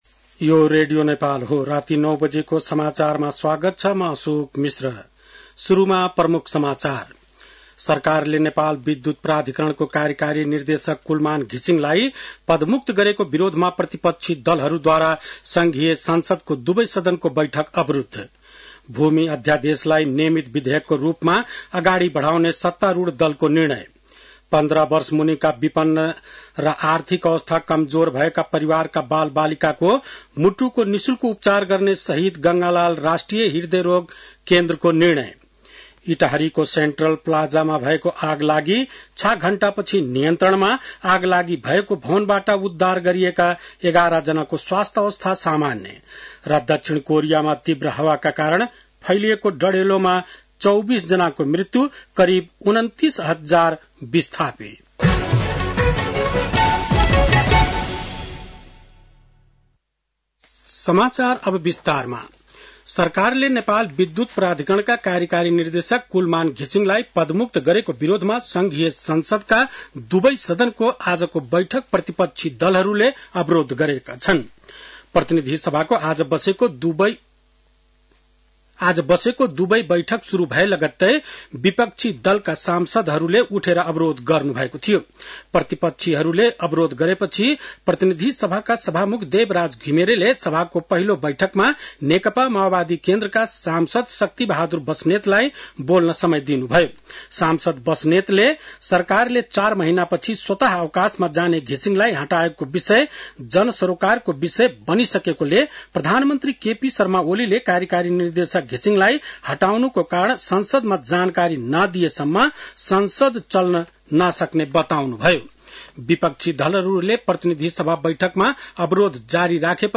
An online outlet of Nepal's national radio broadcaster
बेलुकी ९ बजेको नेपाली समाचार : १३ चैत , २०८१